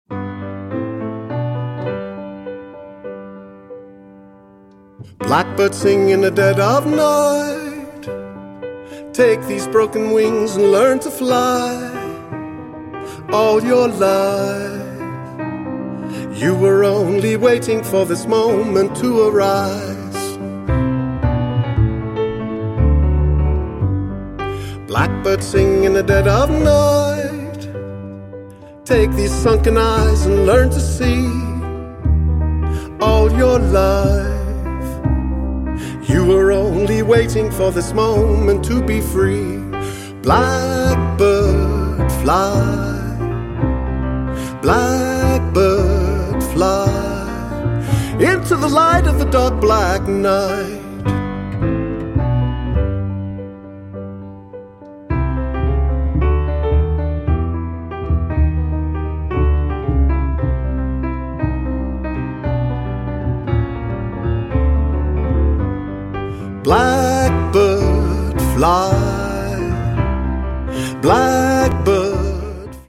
tune created for Piano